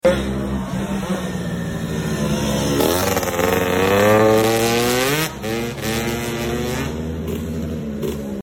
Yamaha jog 101cc racing two sound effects free download